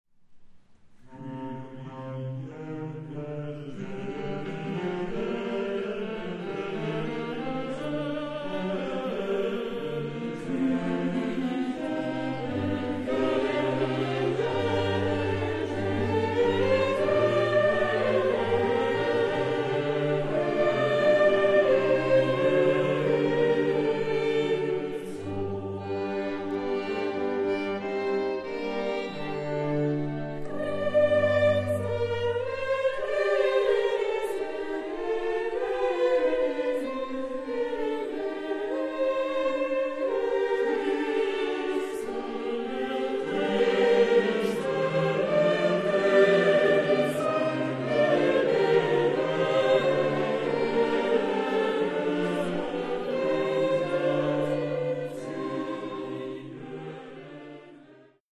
— Ausschnitte aus dem Konzert der Kaufbeurer Martinsfinken in Irsee vom 21.3.10: